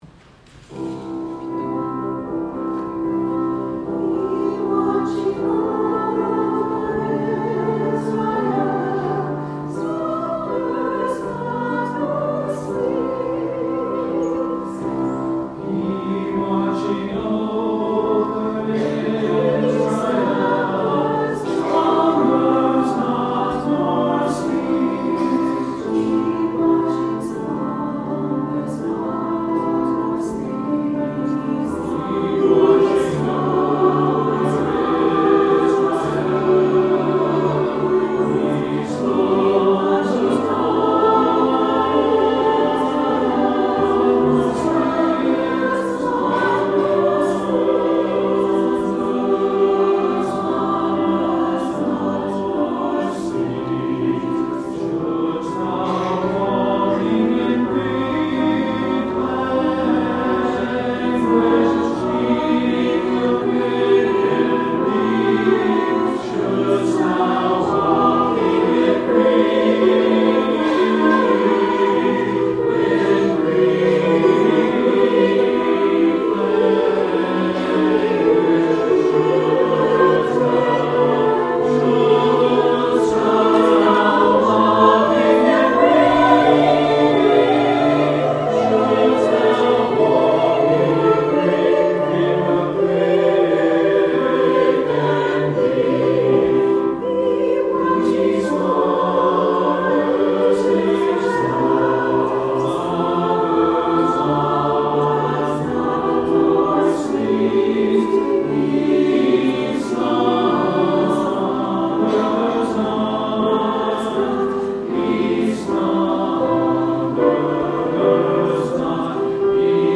Offertory: Chancel Choir